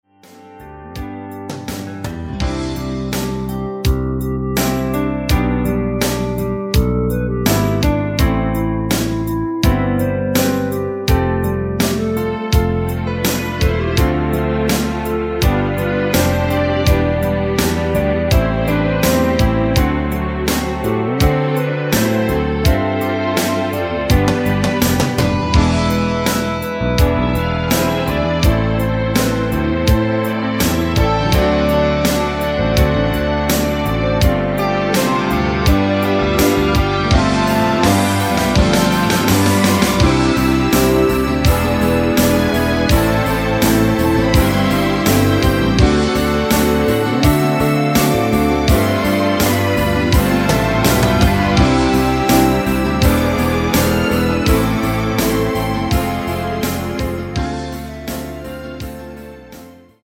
노래방에서 노래를 부르실때 노래 부분에 가이드 멜로디가 따라 나와서
앞부분30초, 뒷부분30초씩 편집해서 올려 드리고 있습니다.
중간에 음이 끈어지고 다시 나오는 이유는